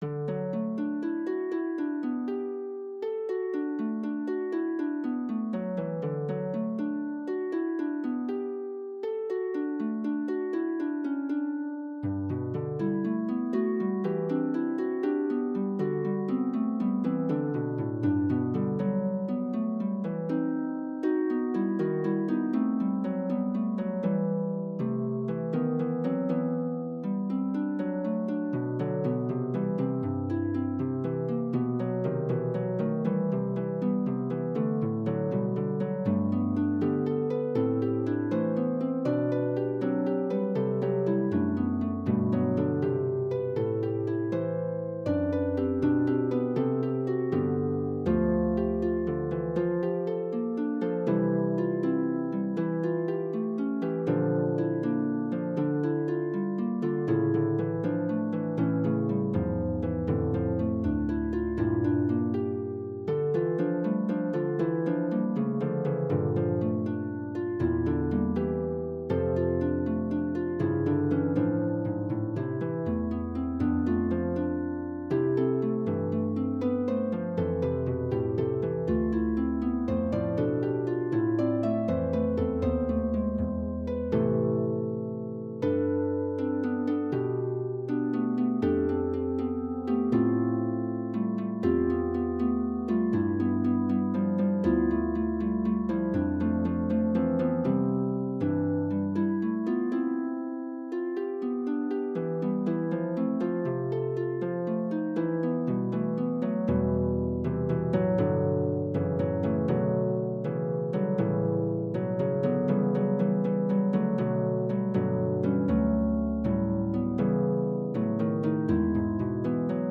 The fugue based on the traditional Irish tune was on the backburner for a while, but I made some tweaks yesterday and hope to have it done sometime soon. I just have the last episode and the final entries and the coda.